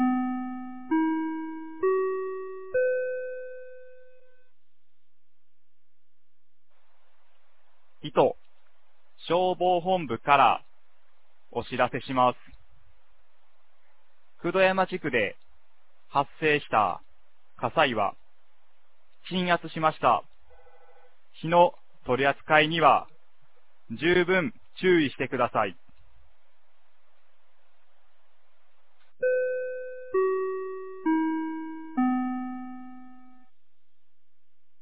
2025年06月05日 09時49分に、九度山町より全地区へ放送がありました。